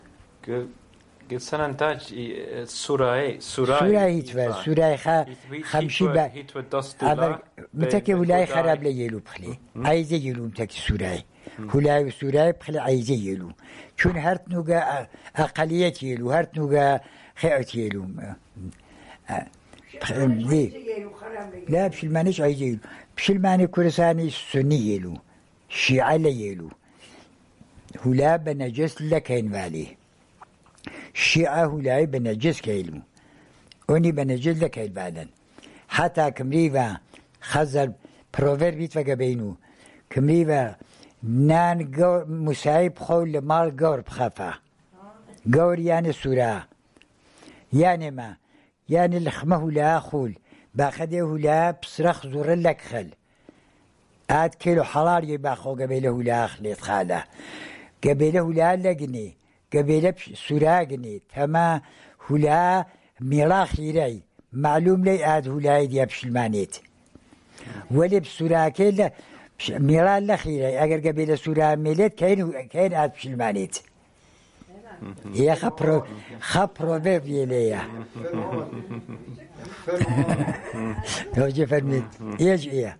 Sanandaj, Jewish: The Christians of Sanandaj